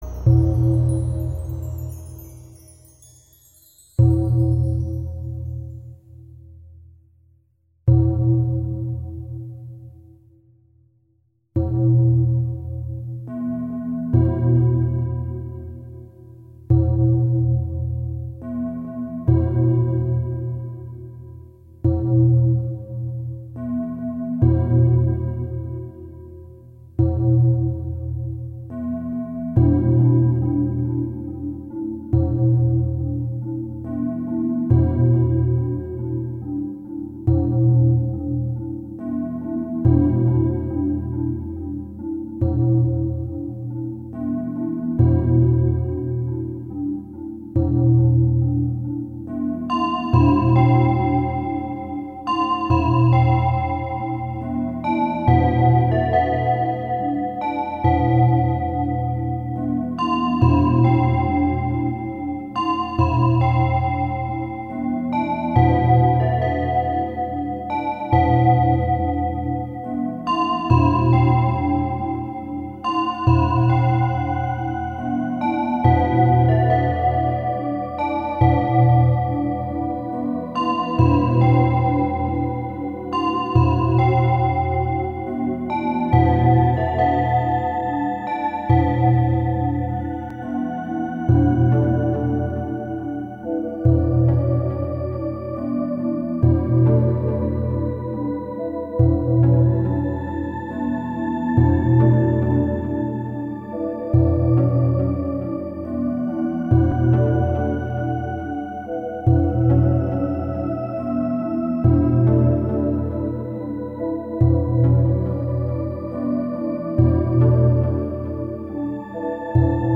其配乐精湛若斯，音符跌宕，随着历史叙述的起伏不断变换着节奏，低沉的打击乐仿佛敲响了永乐朝的大钟